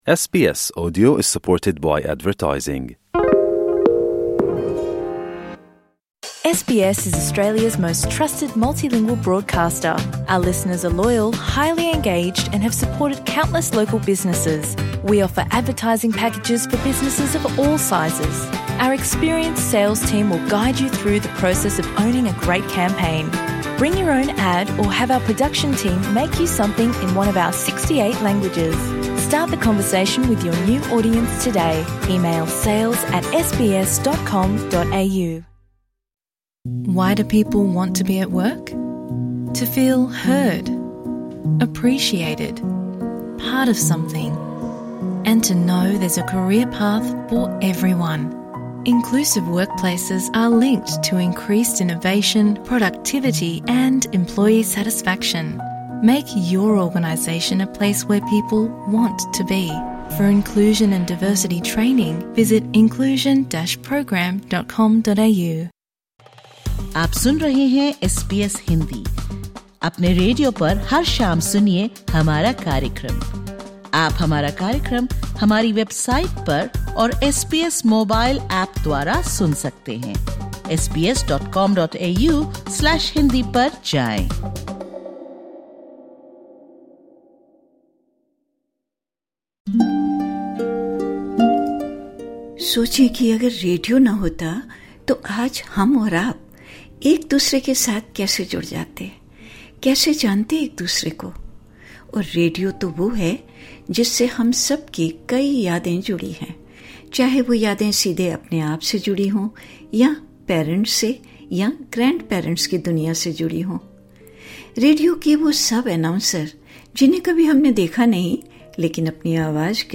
इस पॉडकास्ट में है विश्व रेडियो दिवस के अवसर पर एसबीएस हिन्दी टीम के सदस्यों द्वारा रेडियो से जुड़ी यादें और संदेश।